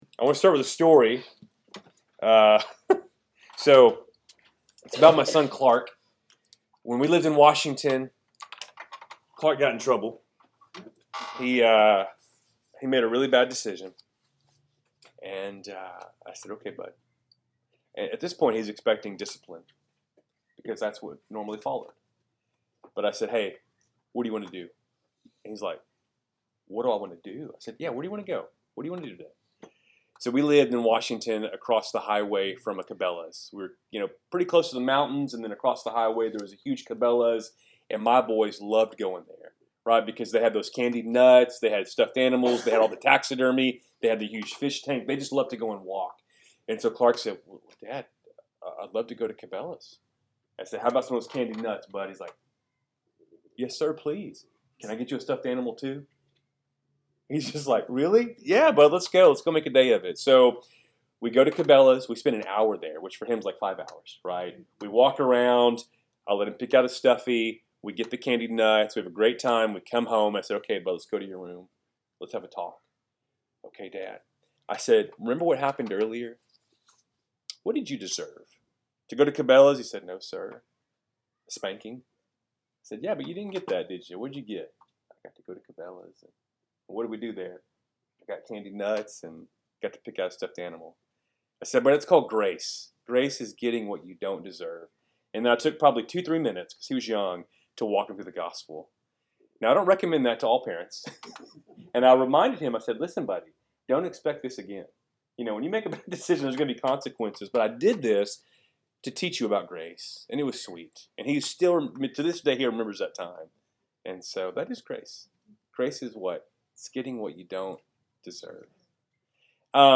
Wednesday Night Bible Study, February 26, 2025